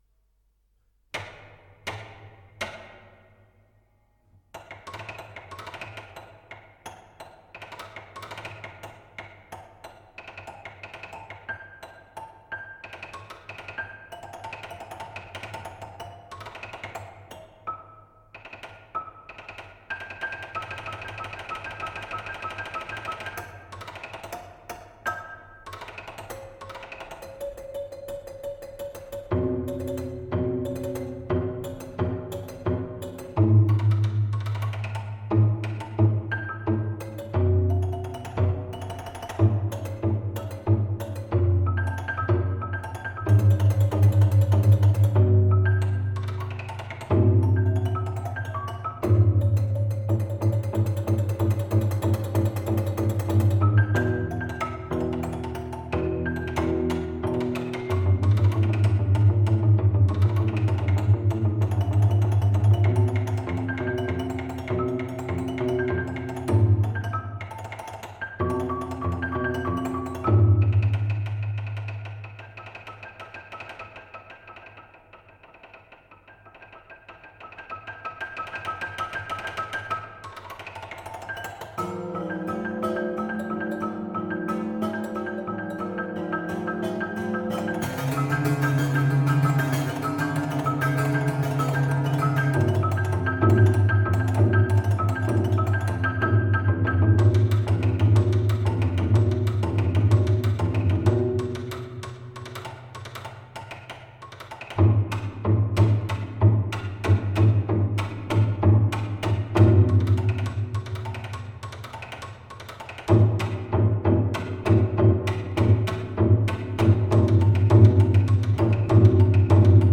composition et piano